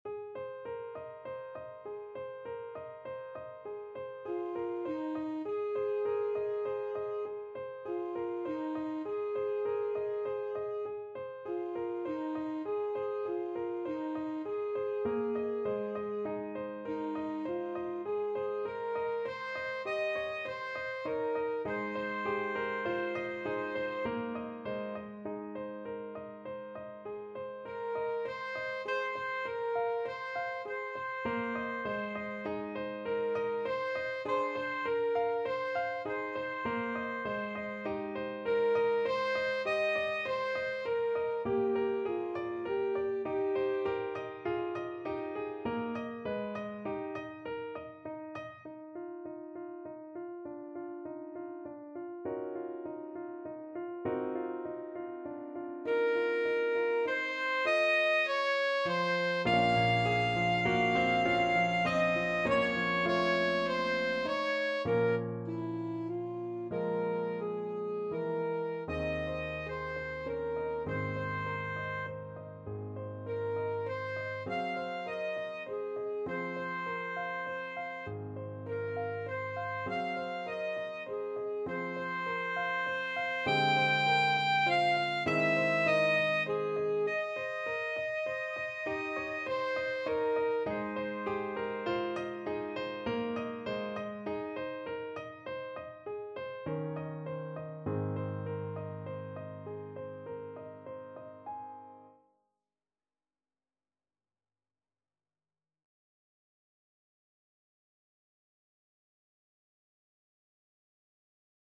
Alto Saxophone version
Alto Saxophone
9/4 (View more 9/4 Music)
Eb5-G6
~ = 120 Allegretto
Classical (View more Classical Saxophone Music)